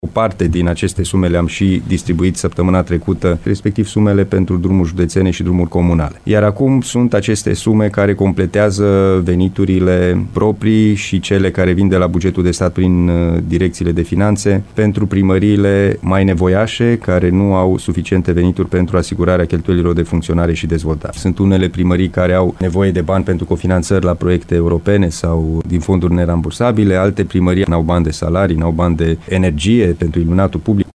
Președintele CJ Timiș, Alin Nica, a precizat, la Radio Timișoara, că în ședința extraordinară, din 11 ianuarie, se vor vota bugetele pentru unitățile administrativ-teritoriale din județ.